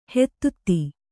♪ hettutti